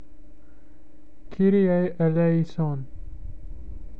Kee-ree-ae   ae-lay-ee-son.